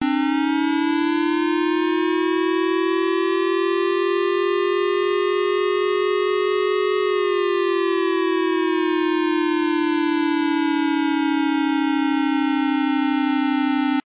国民保護に係る警報のサイレン音
サイレン音の再生（MP3）
siren.mp3